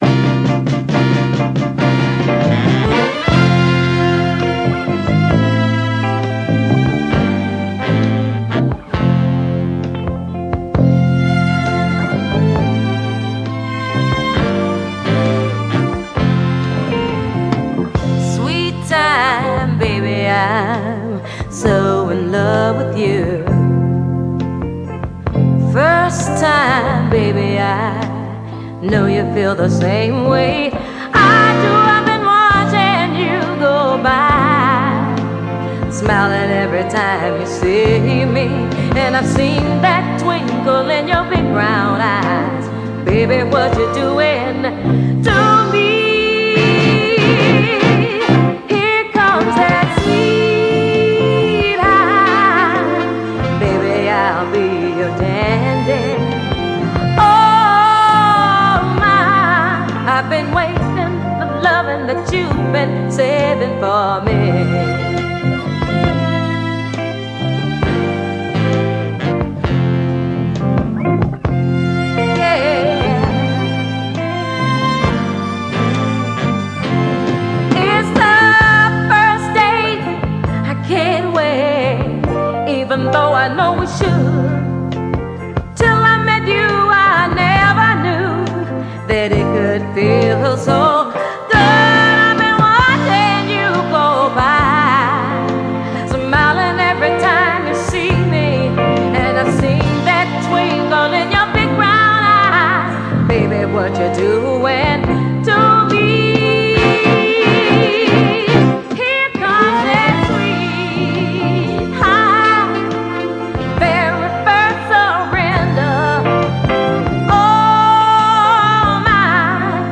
so seelenvolle, von Streichern getragene Ballade
Funk / Soul